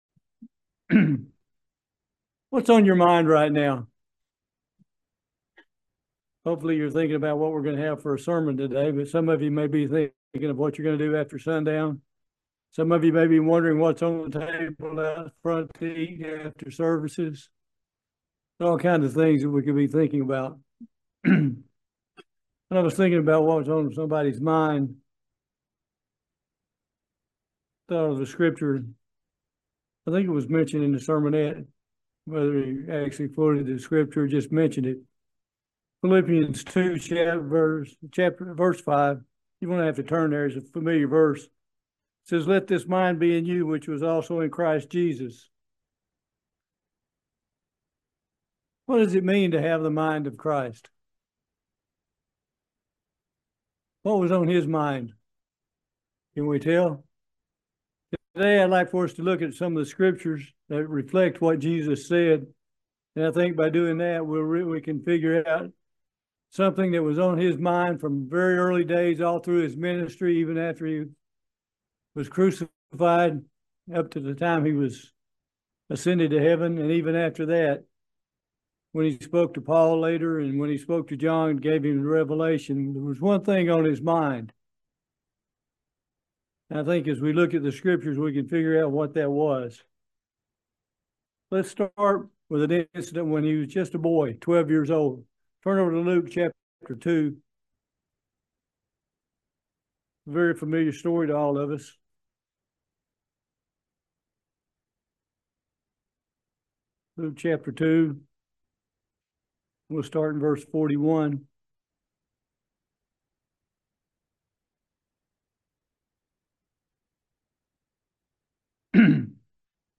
Join us for this video Sermon on the mind of Christ. This sermon looks at scriptures that speak to what Jesus Christ was thinking , and being about His Fathers business.
Given in Lexington, KY